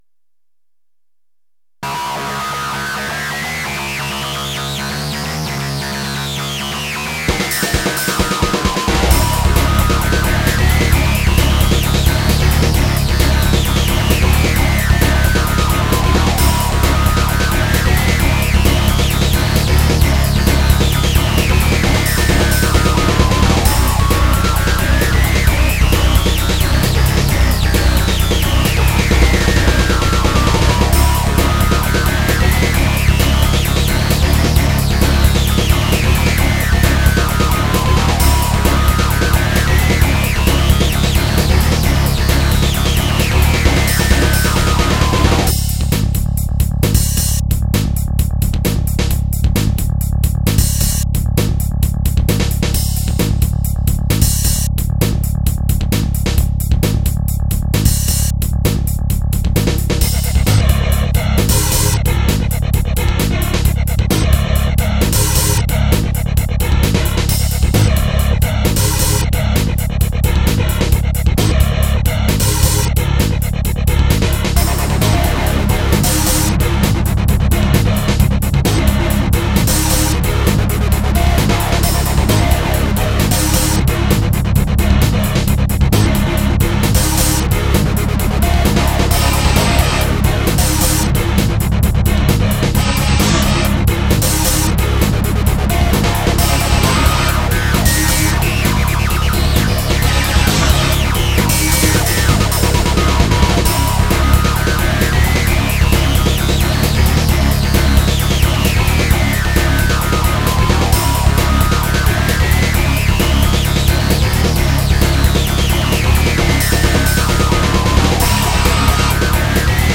Genre：Big Beat